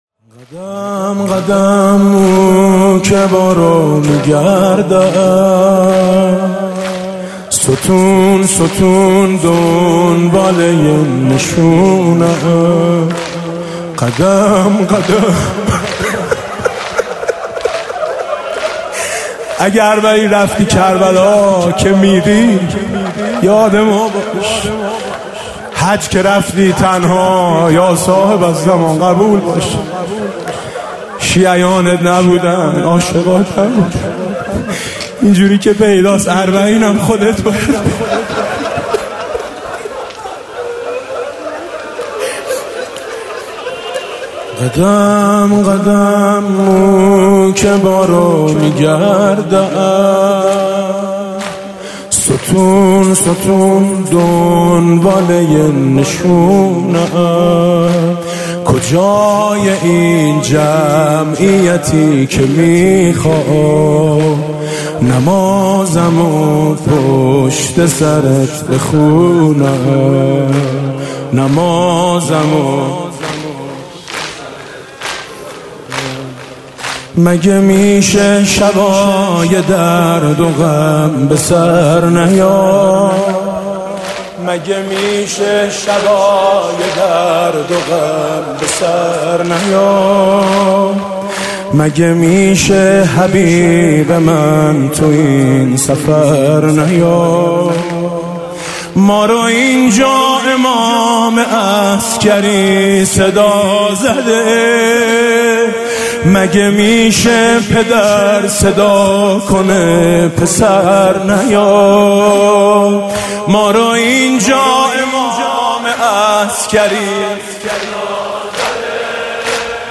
مداحی شب دوم محرم 1399 با نوای میثم مطیعی
آخرين خبر/ مداحي شب دوم محرم 1399 با نواي ميثم مطيعي، هيئت ميثاق با شهدا